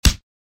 удар по голове _лицу.ogg